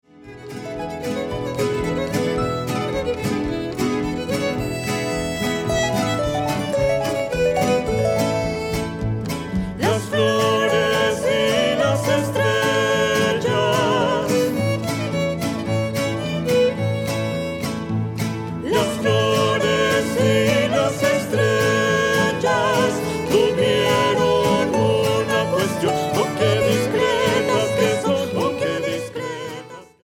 Versiones mariachi